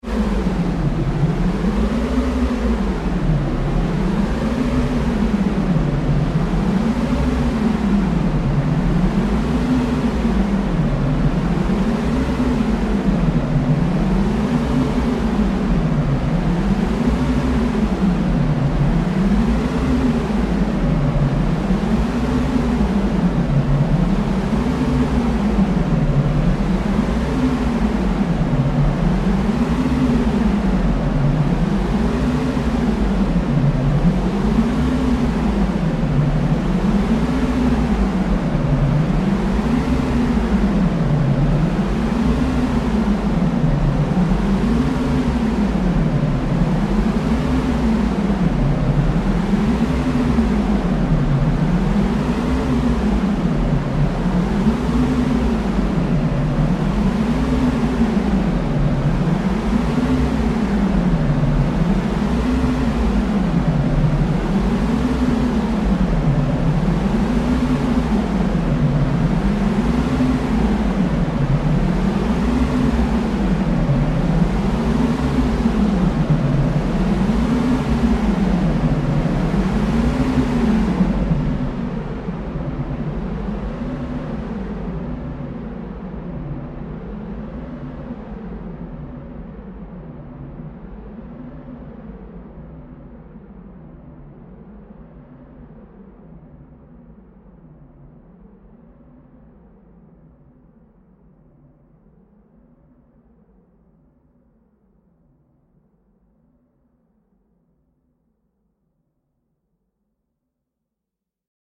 Extra Long Sound Effect - 1m 51s
Use This Extra Long Premium Hollywood Studio Quality Sound In Stereo.
Channels: 2 (Stereo)
This Premium Quality Futuristic Sound Effect
Tags: long large alien beam beams future futuristic laser sci-fi science fiction scifi